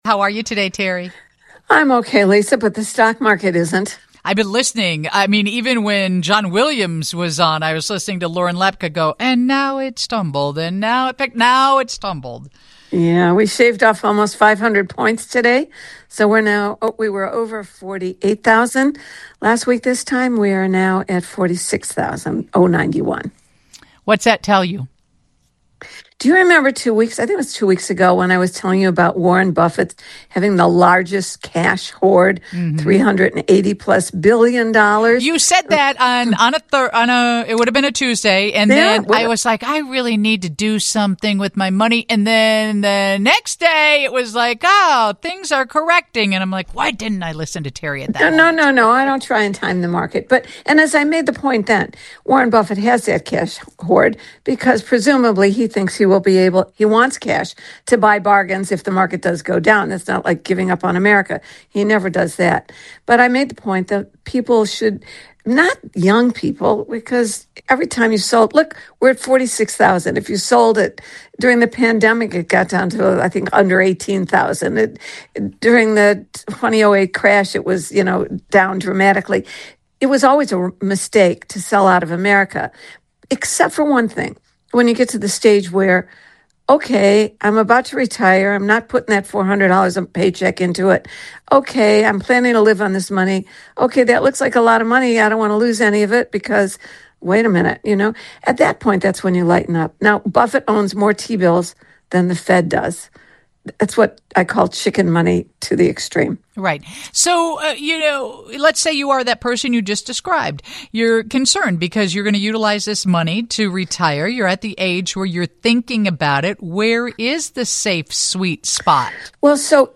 She shares how, in the midst of stock market troubles, people should reassess where they have their money saved. And, as always, she answers questions from listeners.